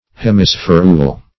Hemispherule \Hem`i*spher"ule\, n. A half spherule.